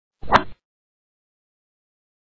daqiu.wav